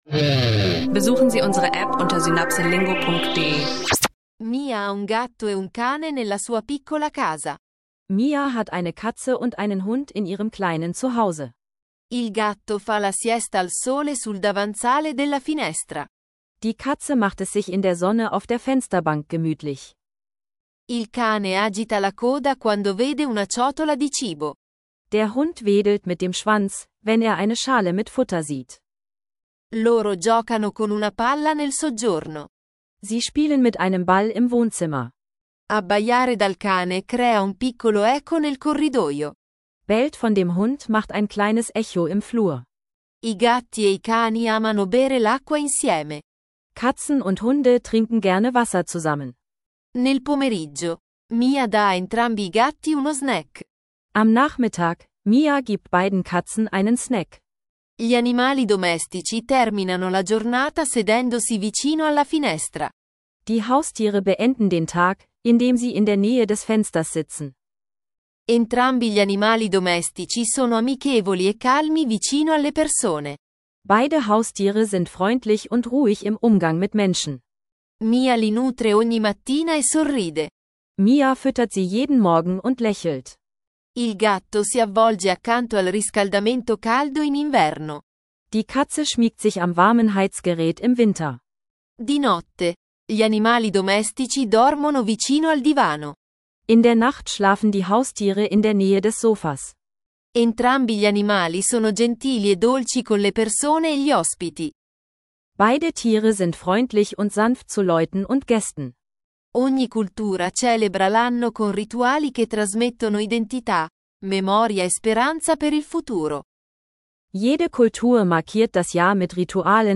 Lerne alltägliche italienische Sätze rund um Katzen und Hunde – mit einfachen Dialogen, Vokabeln und Phrasen.